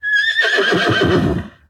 whinny2.ogg